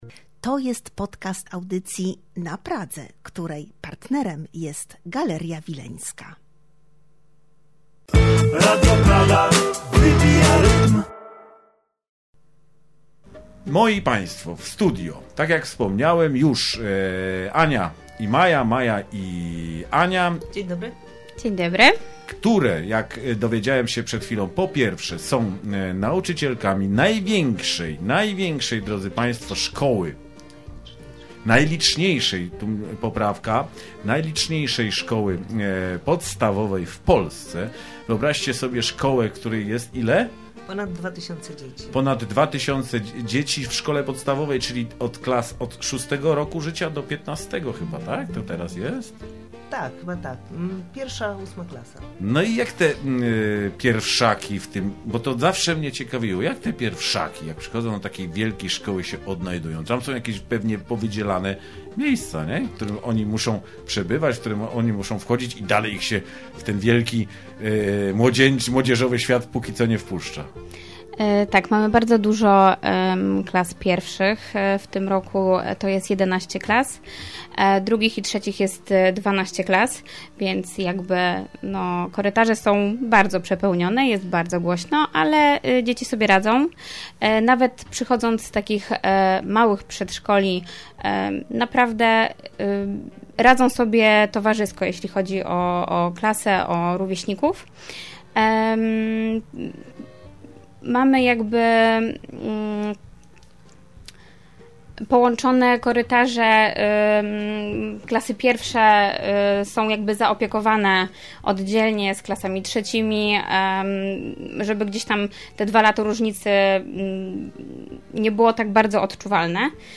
To bardzo ciekawa i potrzebna rozmowa.